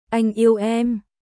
Anh yêu emアイン イェウ エム私（男）はあなた（女）を愛しています